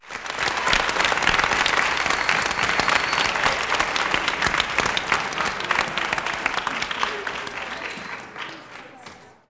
clap_021.wav